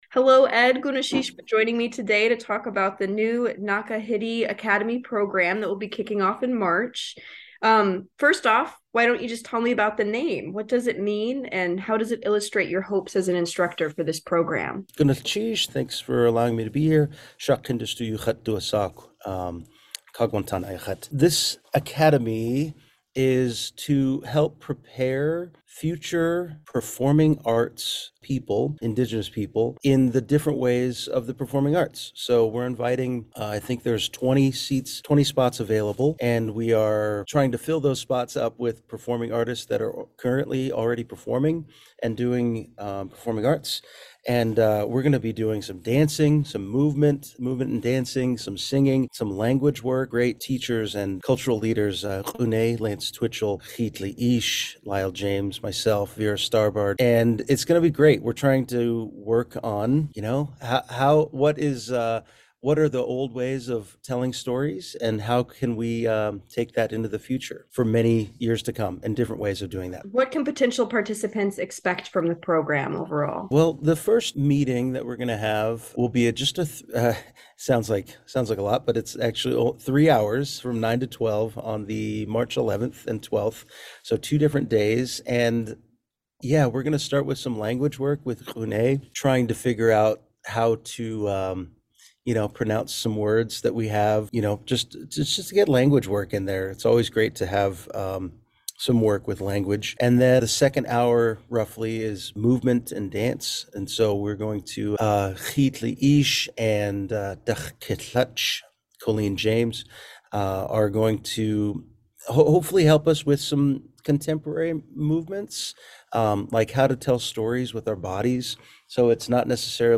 This video interview is edited to improve clarity and length.